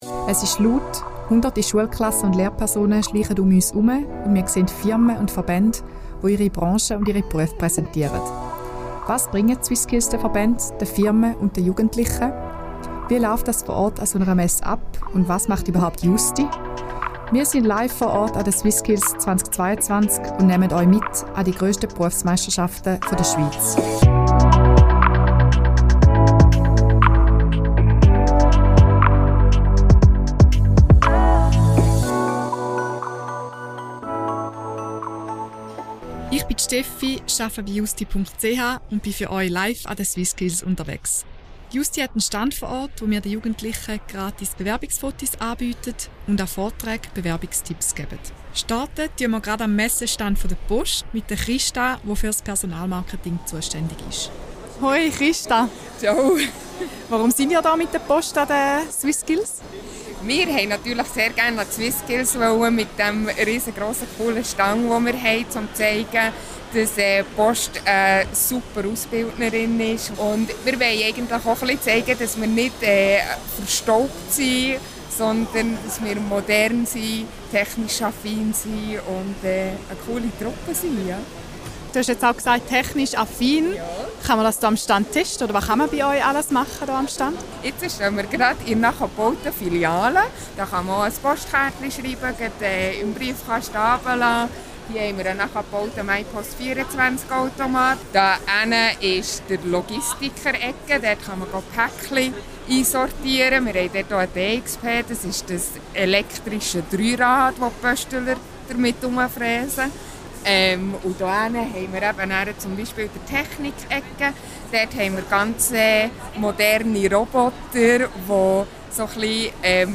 Wir sind live vor Ort an den SwissSkills 2022 und nehmen euch mit an die grösste Berufsmeisterschaft der Schweiz.